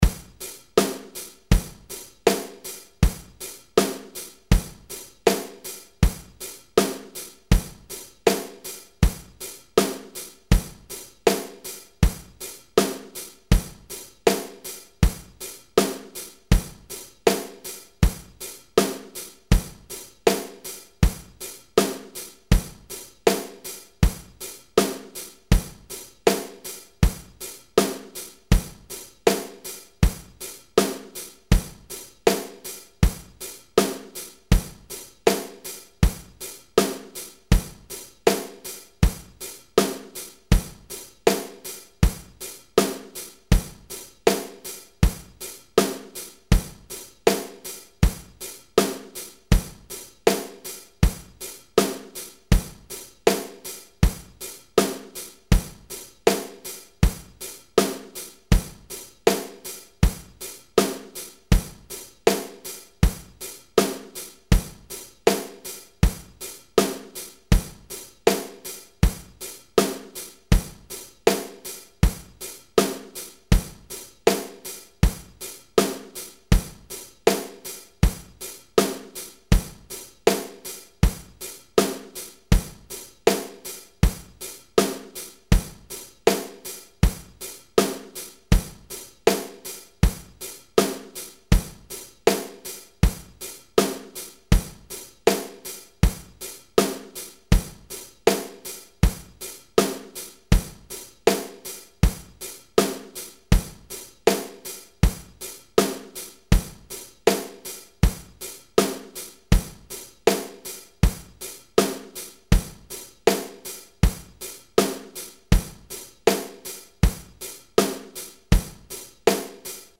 Drum Beats
Each drum beat file is a 2 minute long loop of a drummer playing a straight 4/4 beat.
80 Beats per minute
80BPM.mp3